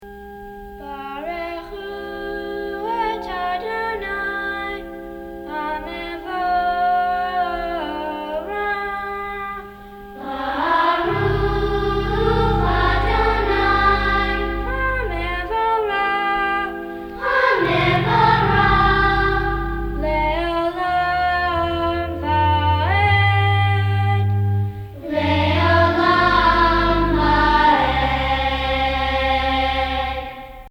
“Barchu” from Junior Choir Songs for the High Holy Days.